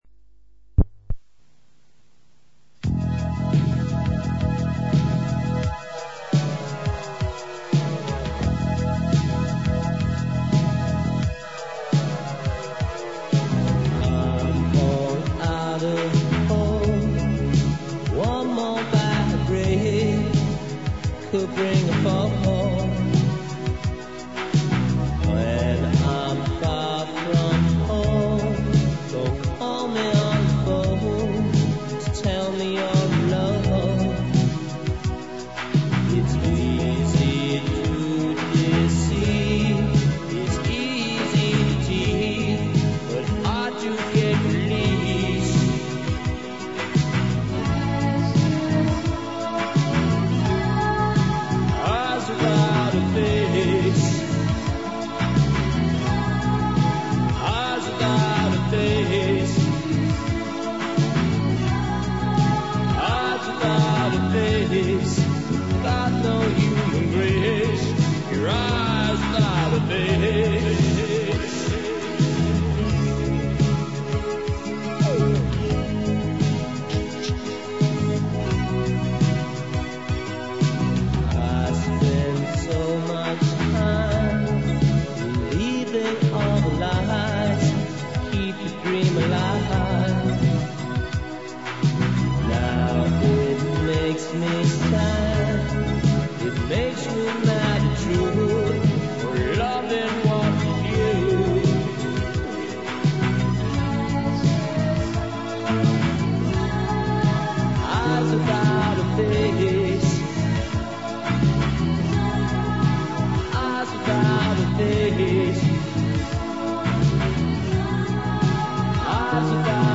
22kHz Mono